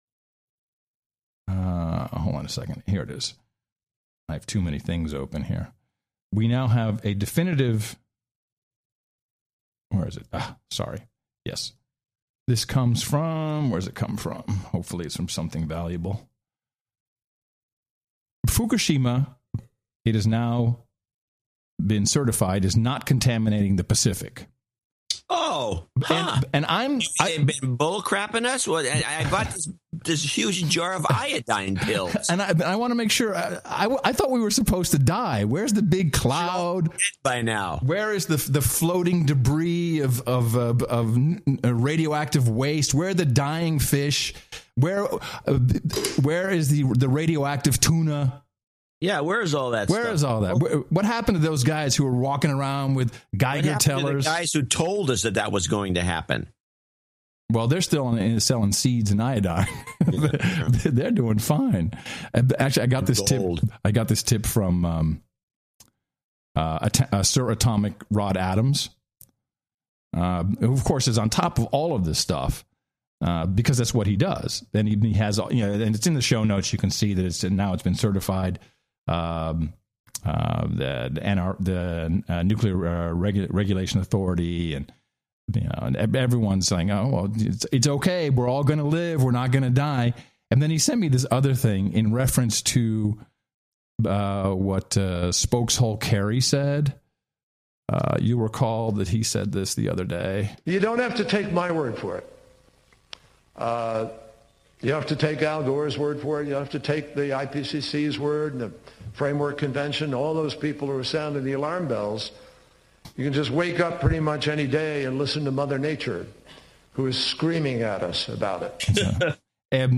Warning – no topic is sacred to the No Agenda show hosts. They are willing to insult anyone and sometimes use adult language.